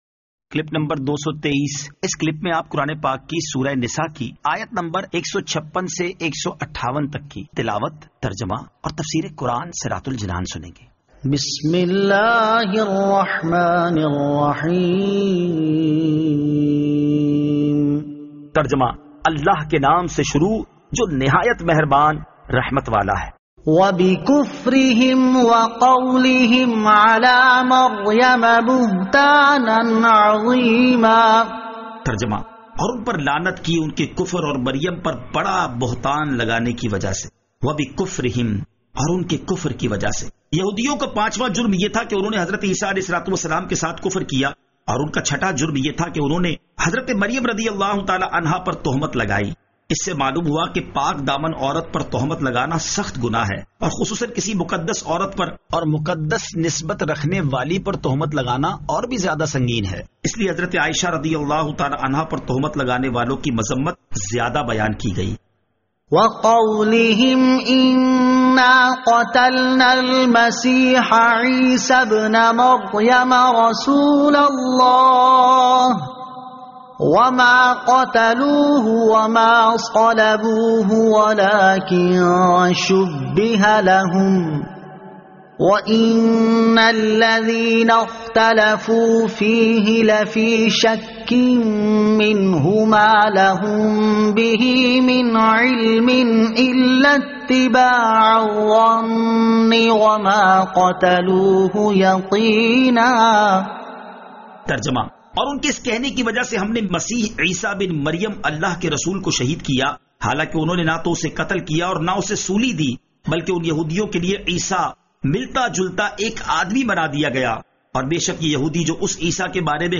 Surah An-Nisa Ayat 156 To 158 Tilawat , Tarjama , Tafseer